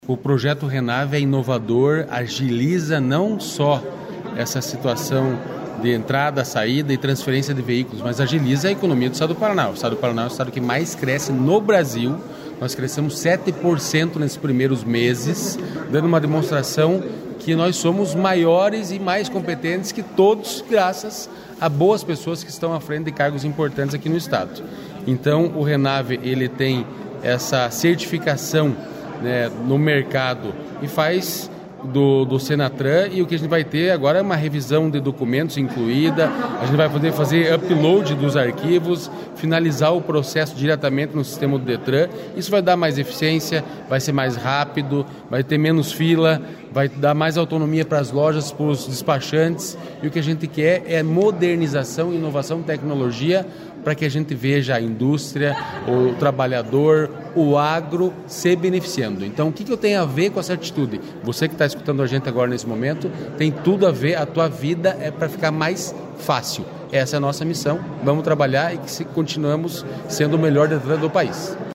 Sonora do diretor-presidente do Detran, Santin Roveda, sobre a regulamentação do sistema que agiliza revenda de veículos usados